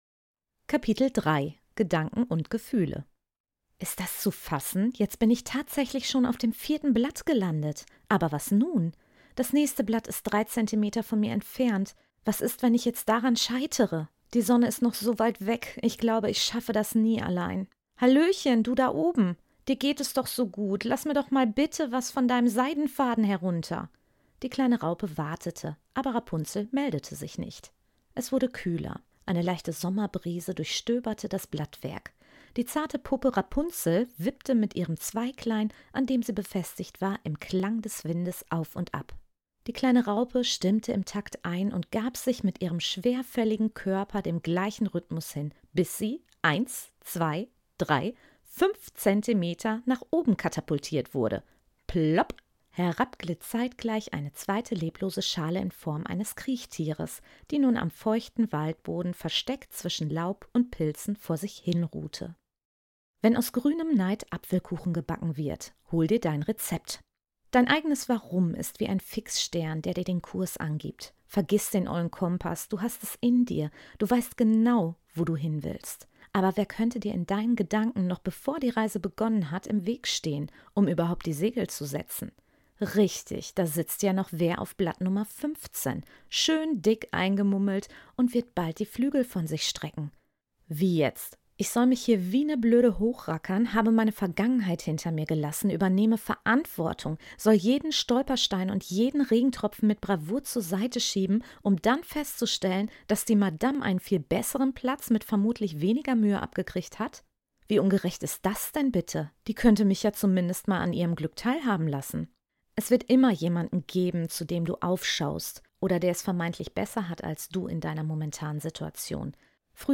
Loslassen, träumen, genießen – genau das erwartet dich in diesem Hörbuch. Es ist meine liebevolle Einladung, dich zurückzulehnen und dich auf eine bunte, transformierende Reise zu deinem wahren ICH zu begeben.